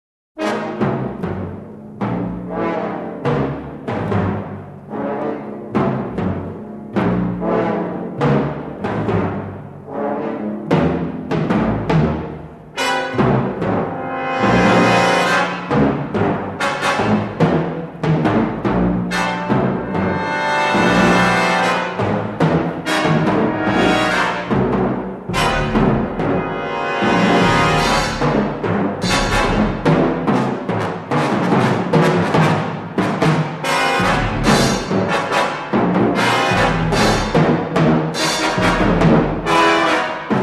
sole surviving mono mixdown safety master